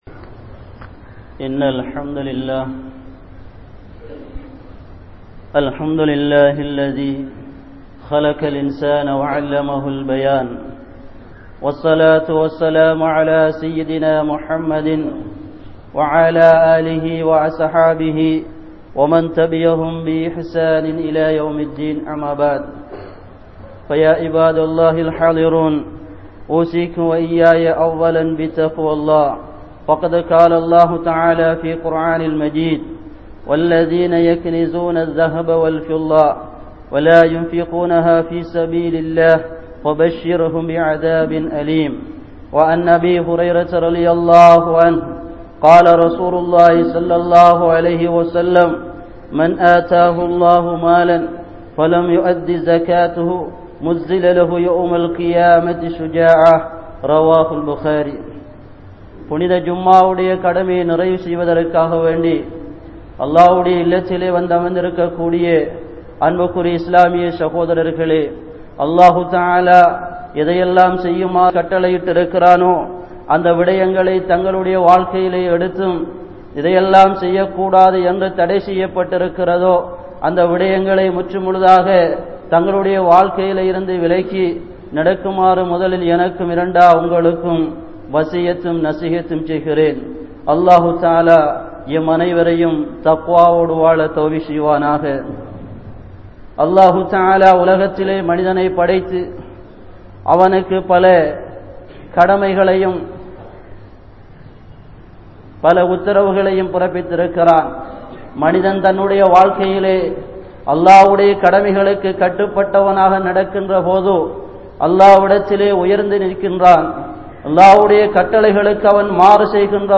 Zakath | Audio Bayans | All Ceylon Muslim Youth Community | Addalaichenai
Grand Jumua Masjith